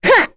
Rodina-Vox_Attack1.wav